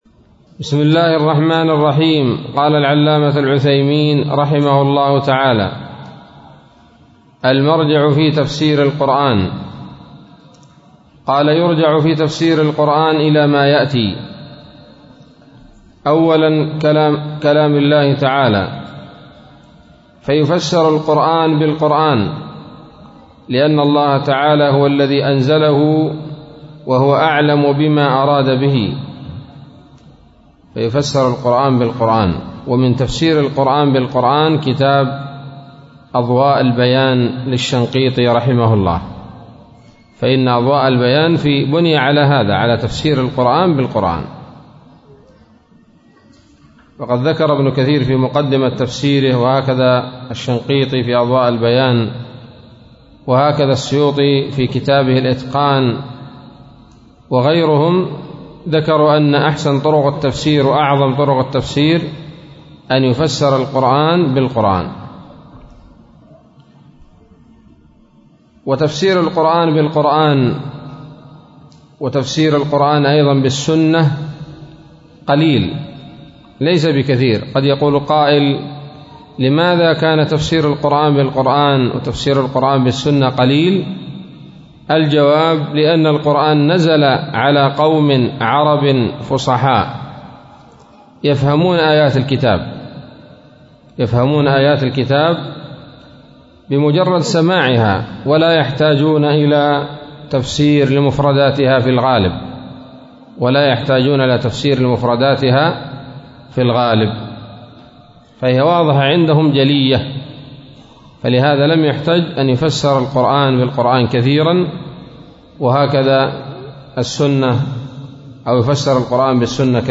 الدرس العشرون من أصول في التفسير للعلامة العثيمين رحمه الله تعالى